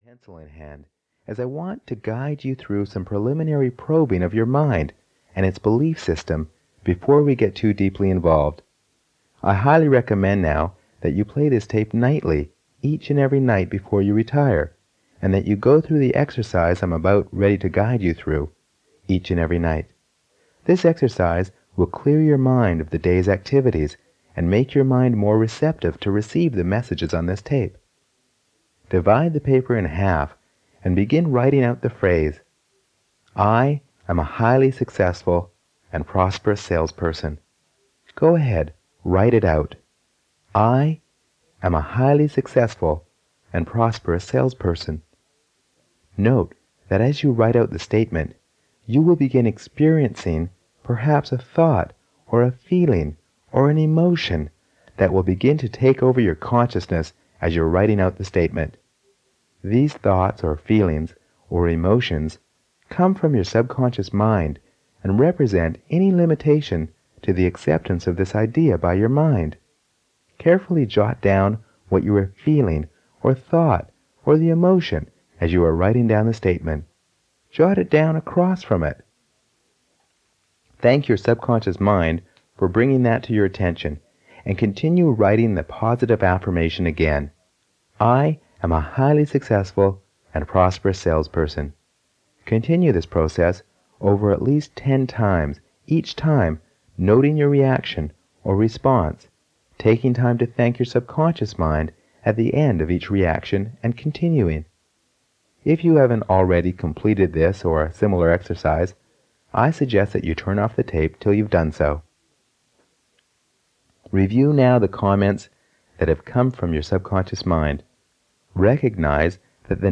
Sales Power 1 (EN) audiokniha
Ukázka z knihy